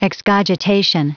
Prononciation du mot excogitation en anglais (fichier audio)